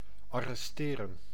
Ääntäminen
arrêter France (Paris)